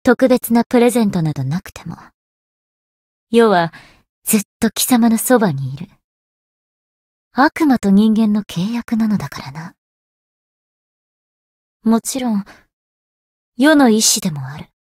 灵魂潮汐-萨缇娅-春节（送礼语音）.ogg